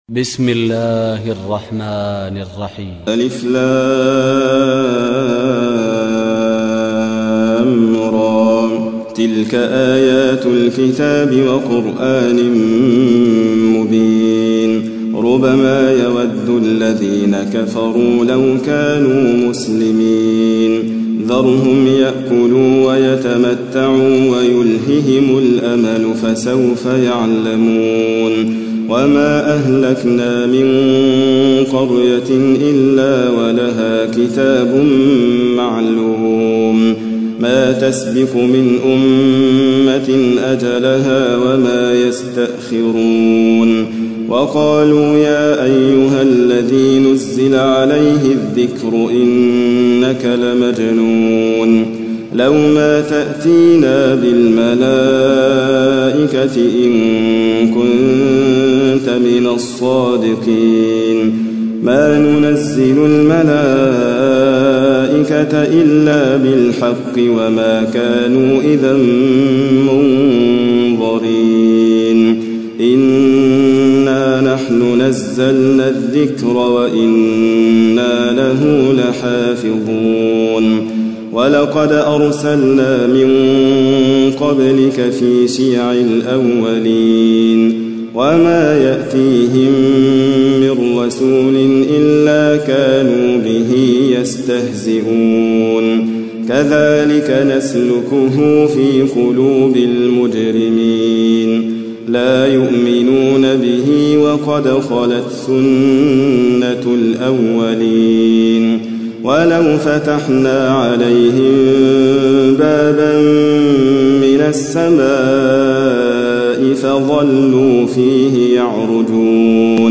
تحميل : 15. سورة الحجر / القارئ حاتم فريد الواعر / القرآن الكريم / موقع يا حسين